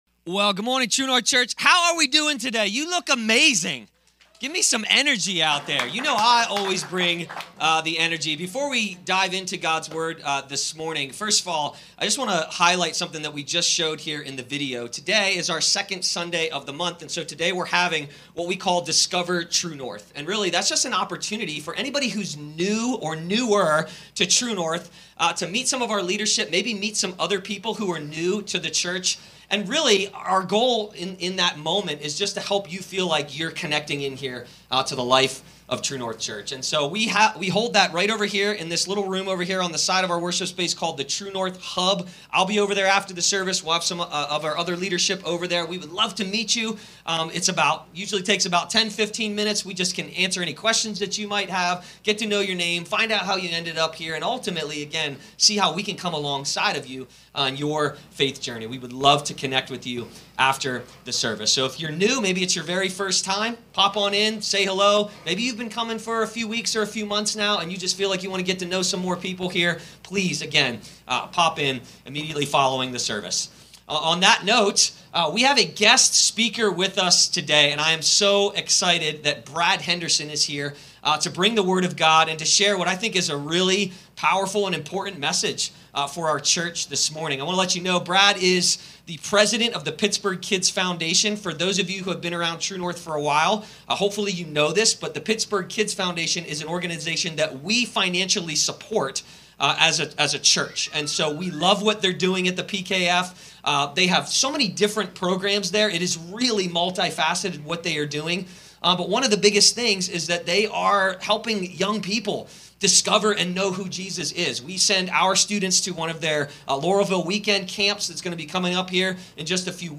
Who’s your neighbor? | Guest Speaker